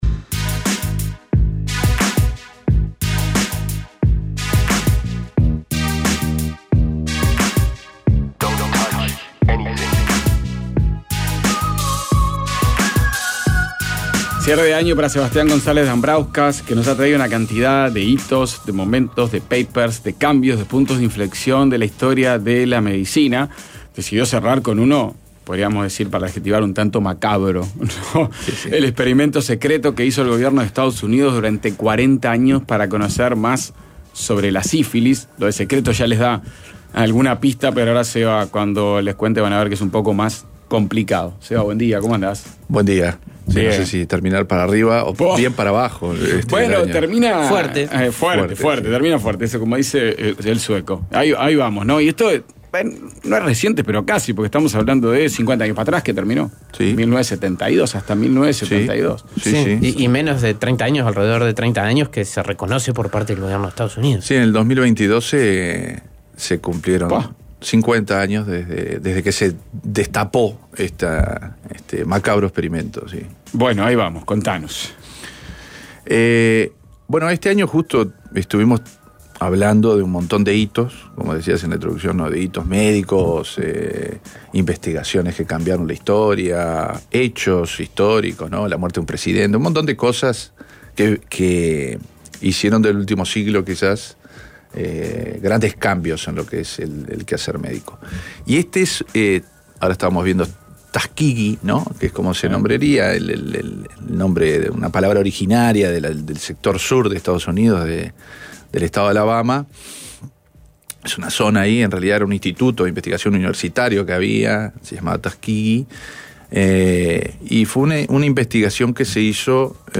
Columna